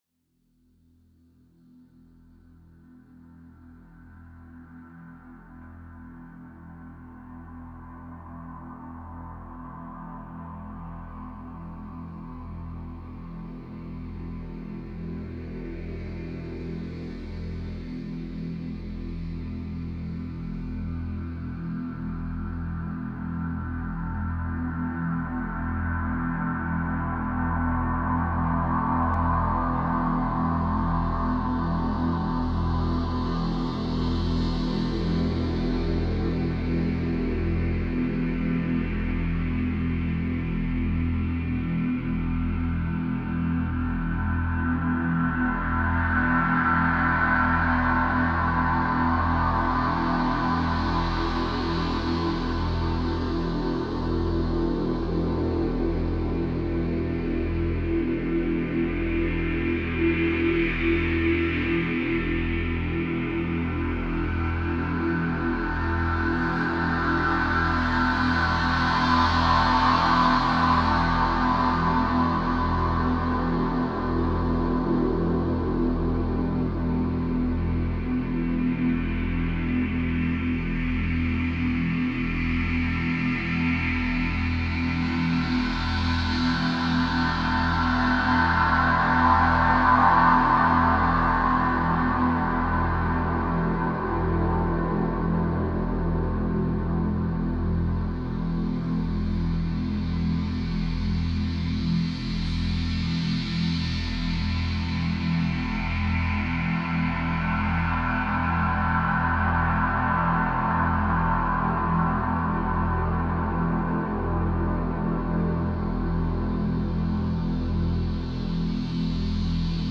Ambient track inspired by my first winter here in Iceland. It is a work in progress and part of a project about winter. Small problem at the end on the winder soundscape loop !
The main moving sound is a field recording of the wind processed through 5 carefully tuned band pass filers and a clever comb filter. The levels and pannings of this sound are controlled by an iPad Lemur App with a custom OSC layout using self-generated movements.
light-wind-14-live.mp3